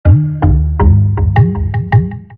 PowerOn.mp3